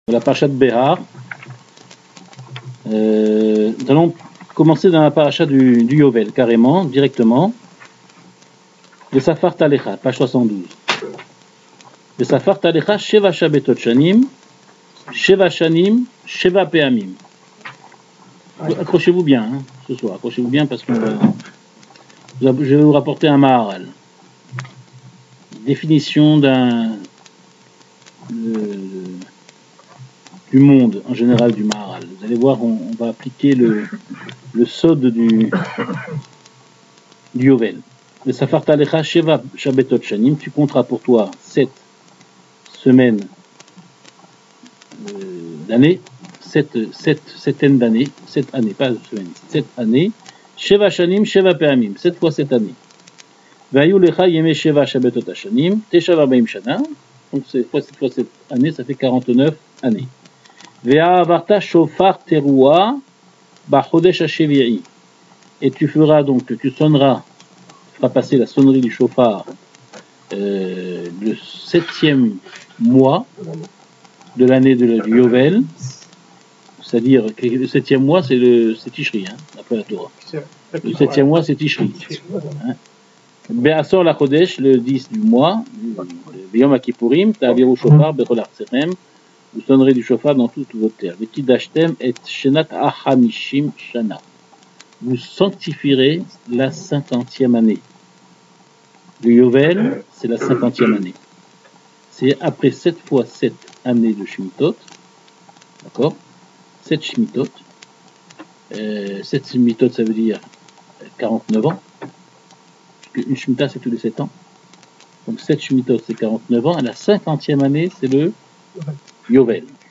Ce cours a été donné le 8 mai 2014